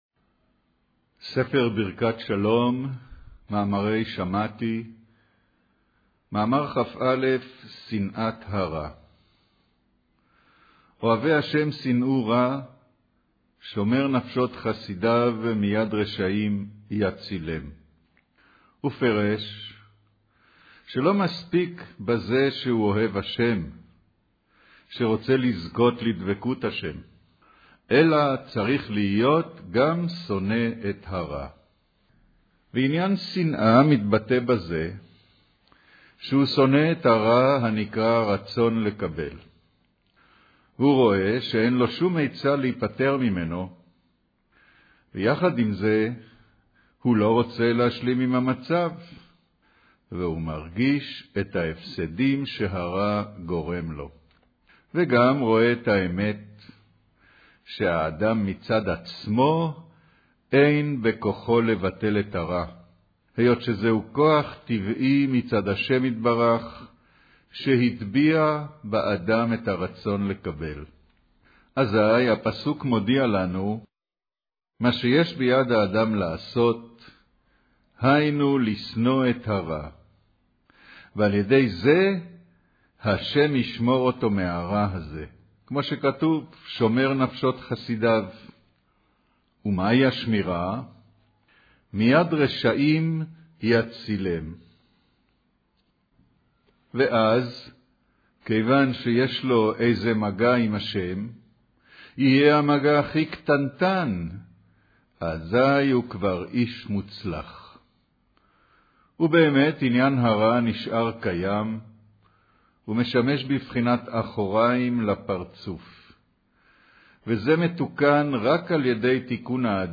אודיו - קריינות